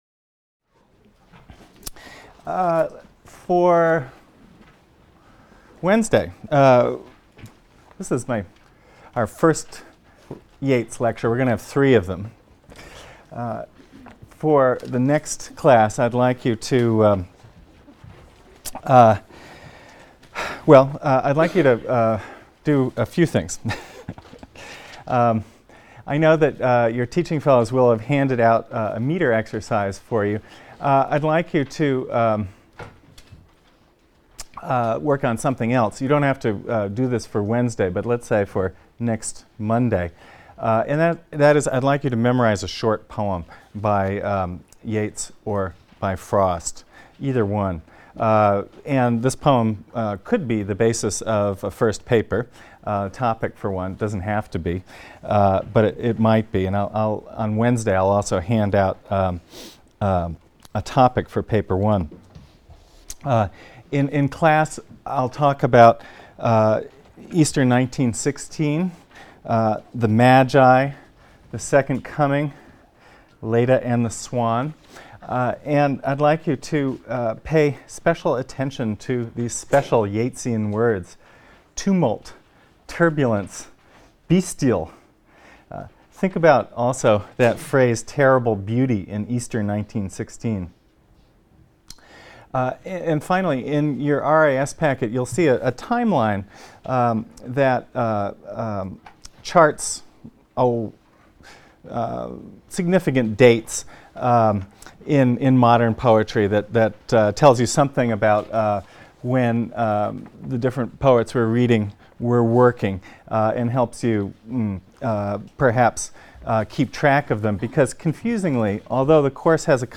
ENGL 310 - Lecture 4 - William Butler Yeats | Open Yale Courses